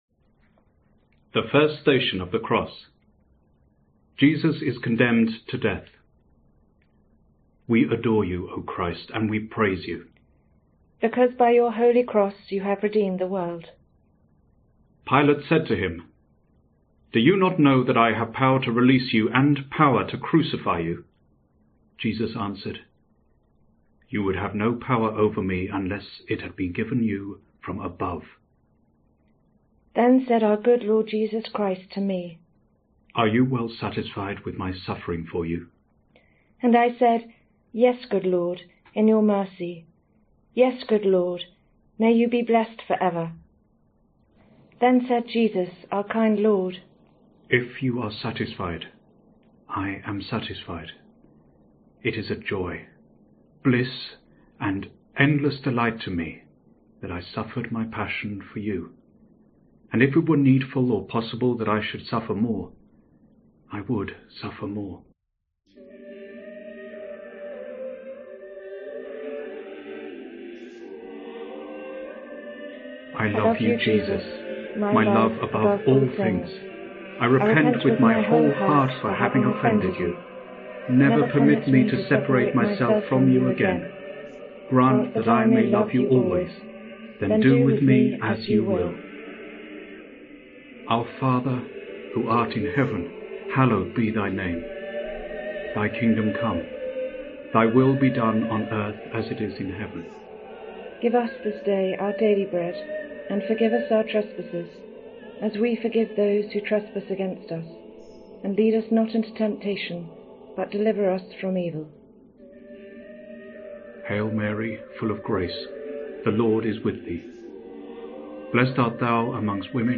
This Stations of the Cross audio is a powerful blend of meditation and prayer known to comfort and calm the soul.